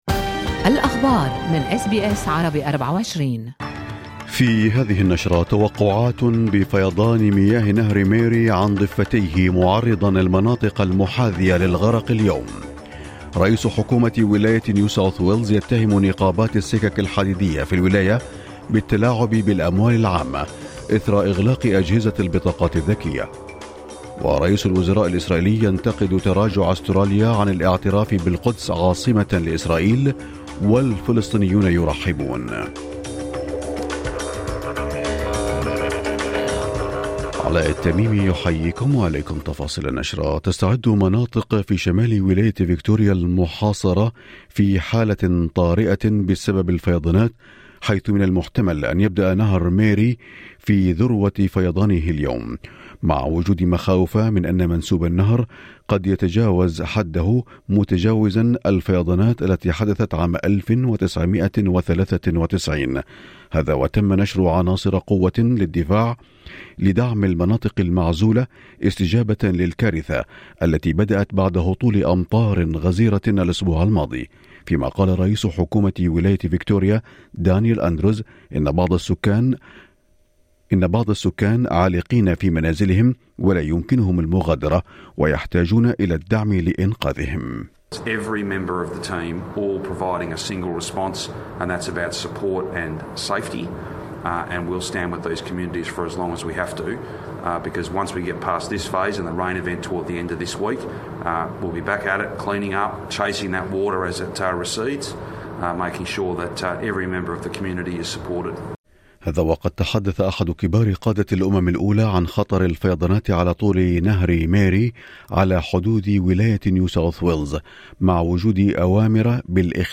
نشرة أخبار الصباح 19/10/2022